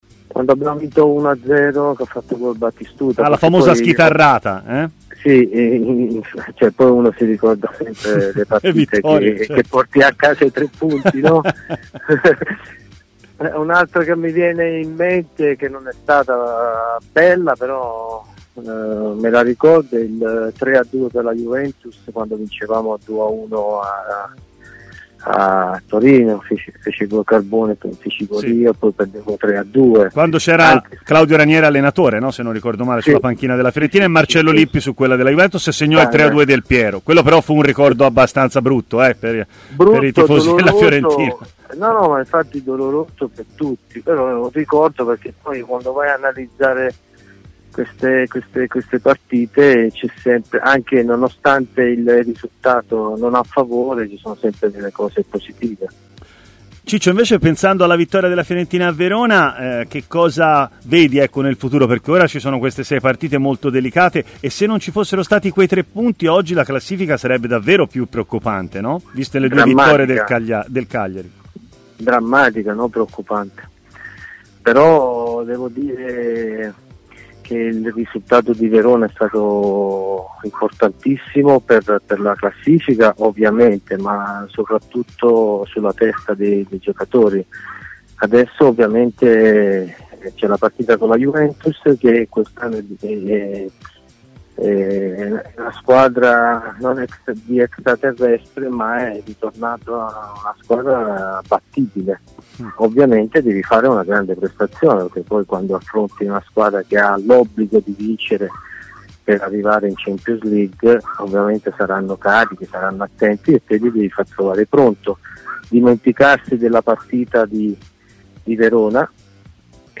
L'ex viola Ciccio Baiano durante la trasmissione "Stadio Aperto" su TMW Radio ha parlato del suo ricordo più bello di Fiorentina-Juve: "L'1-0 con gol di Batistuta... Quando porti a casa i tre punti ricordi sempre meglio! (ride, ndr) Me ne viene in mente un'altra, non bella però: il 3-2 per la Juventus in rimonta, quando vincevamo 0-2, avevo segnato anche io".